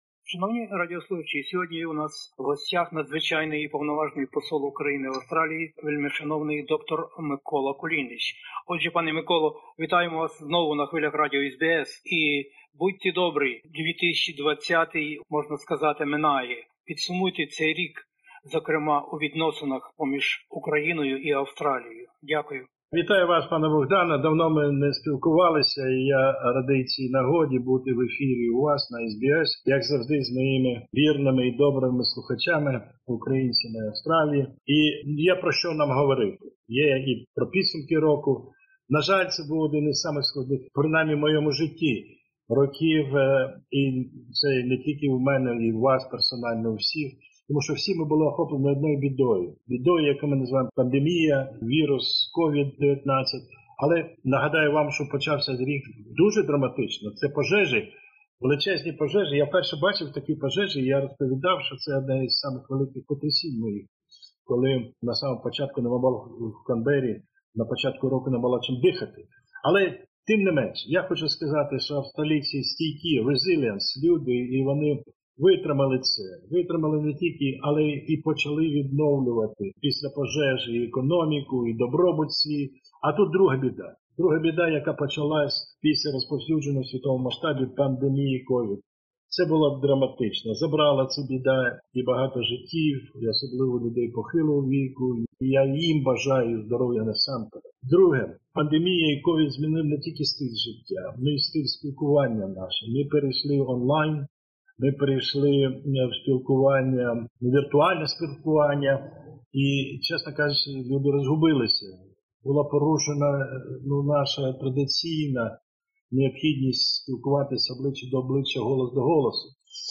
розмовляє із Надзвичайним і Повноважним Послом України в Австралії доктором Миколою Кулінічем - оглядаємося на рік 2020-й і торкаємося розмаїття життя-буття українців та їх нащадків поза Україною...